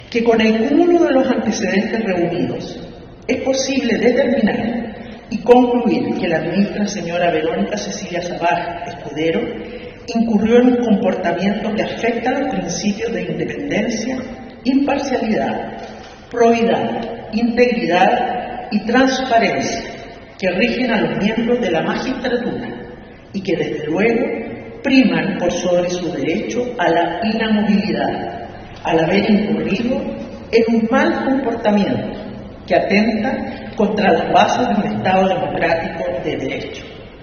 La vocera de la Corte Suprema, María Soledad Melo, explicó que la magistrada “incurrió en un comportamiento que afecta los principios de independencia, imparcialidad, probidad, integridad y transparencia que rigen a los miembros de la magistratura; y que desde luego priman por sobre su derecho a la inamovilidad, al haber incurrido en un mal comportamiento que atenta contra las bases de un Estado democrático de derecho”.
CUNA-VOCERA-SUPREMA.mp3